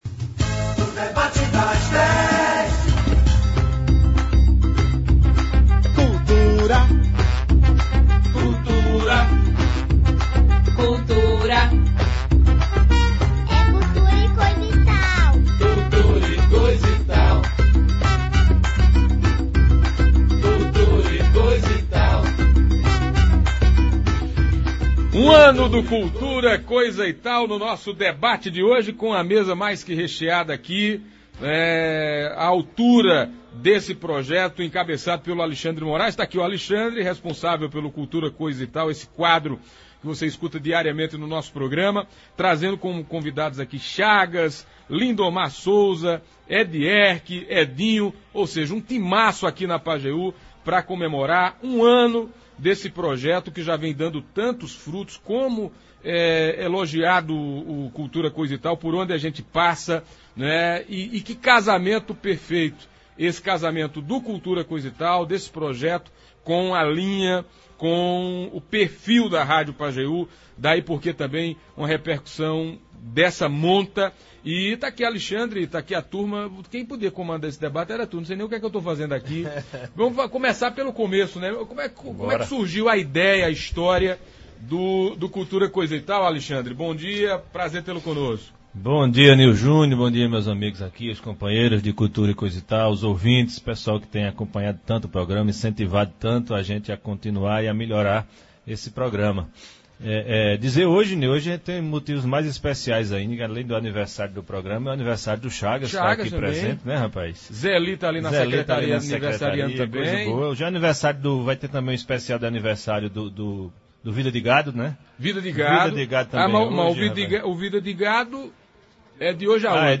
Foram inúmeras participações de ouvintes que enobreceram a qualidade do projeto e sua importância para valorização da cultura sertaneja.
Ouça abaixo na íntegra como foi esse debate repleto de música, poesia e cultura: